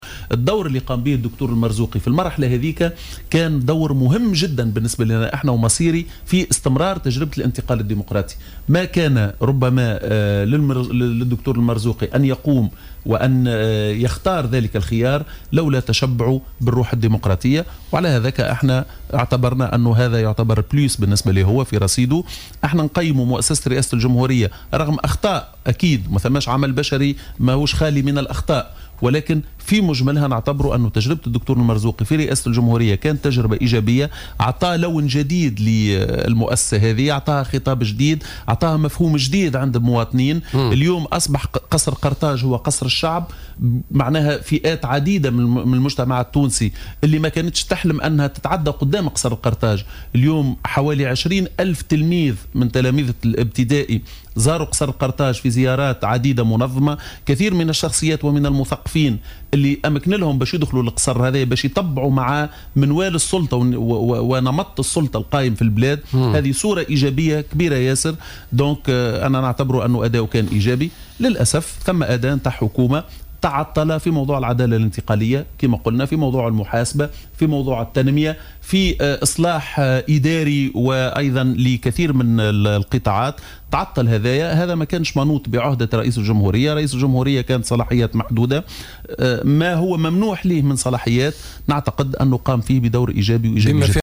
أشاد الأمين العام لحزب البناء الوطني رياض الشعيبي في تصريح للجوهرة أف أم بنجاح رئيس الجمهورية المؤقت، محمد المنصف المرزوقي في إنجاح المسار الديمقراطي معتبرا أن دوره كان مهما ومصيريا في استمرار المرحلة الانتقالية بفضل تشبعه بمبادئ الديمقراطية على حد تعبيره.